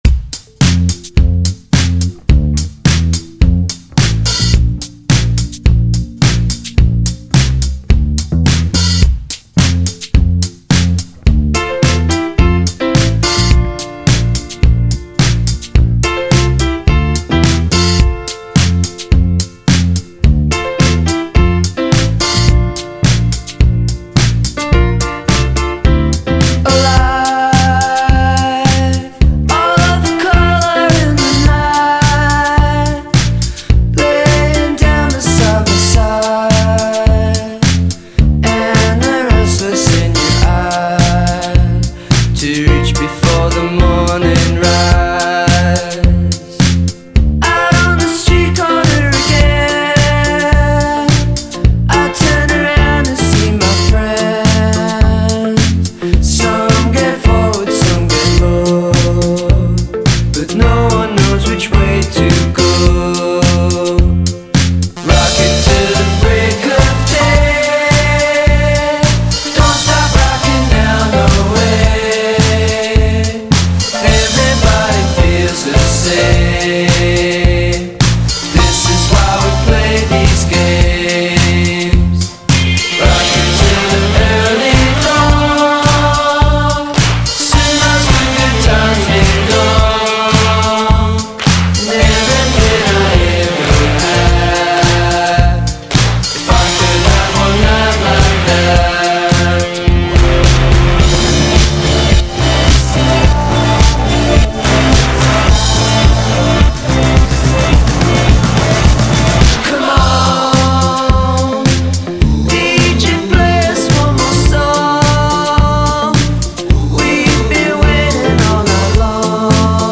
Neat pop-rock sound.